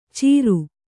♪ cīru